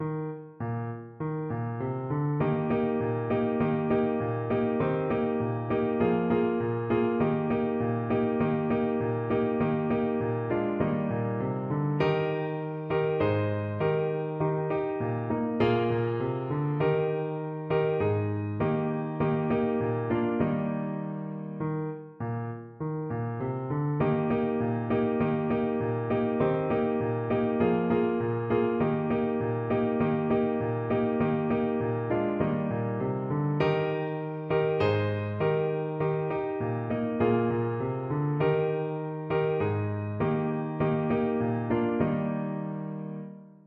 2/4 (View more 2/4 Music)
Brightly
Traditional (View more Traditional Clarinet Music)